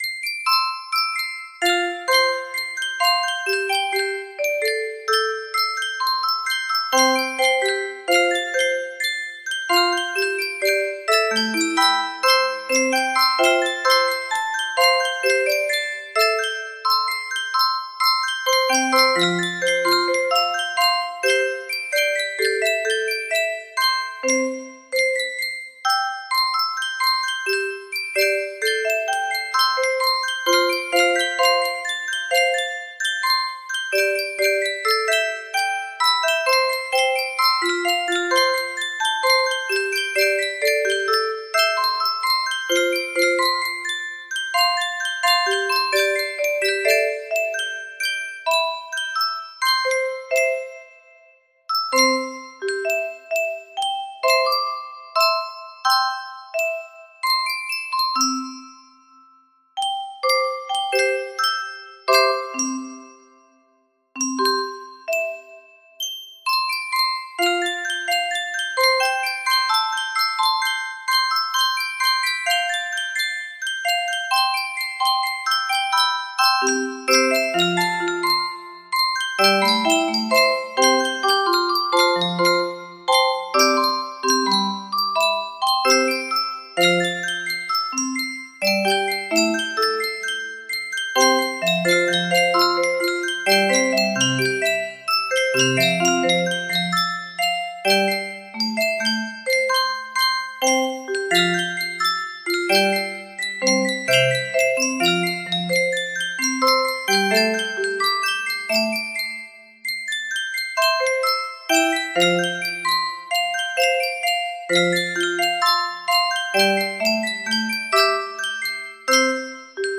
Full range 60
No Reds Proper Tempo